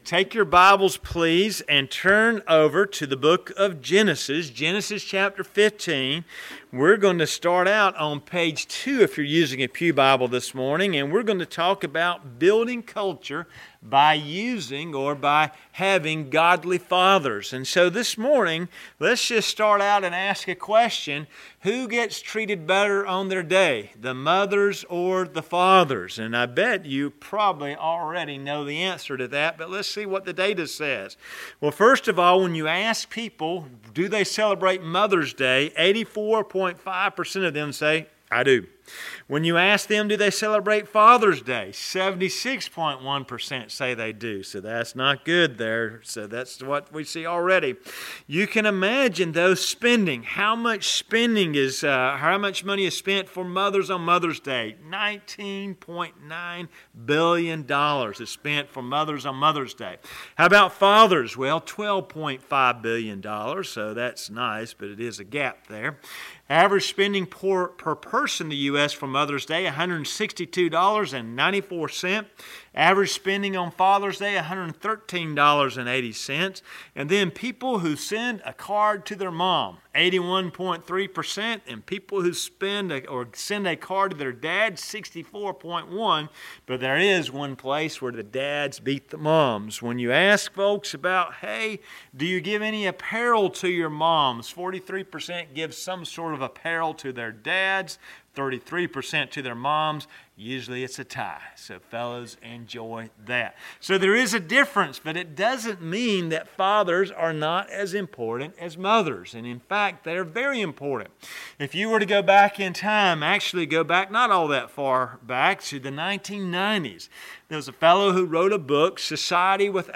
Tippett’s Chapel Church Podcast | Pastor Sermons
Sunday AM Service